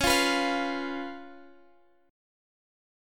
Listen to C#7 strummed